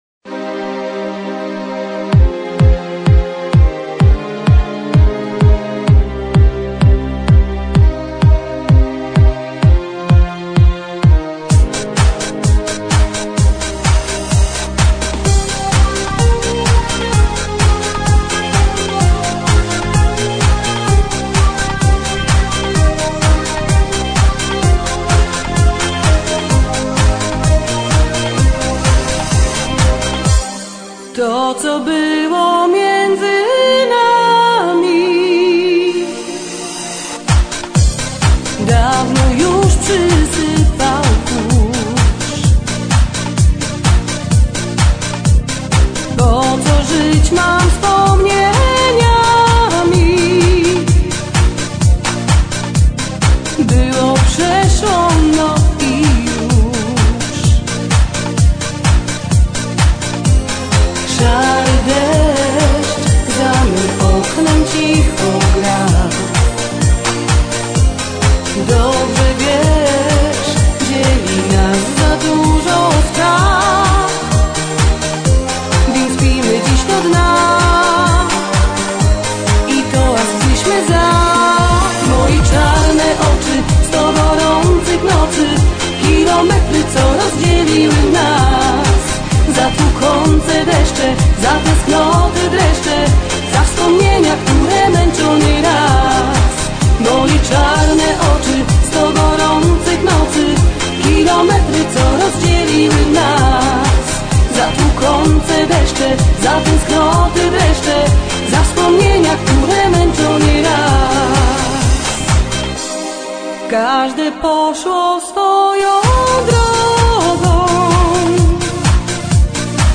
Вход под звучание современной польской песни (
Определите жанр данного музыкального произведения. (песня)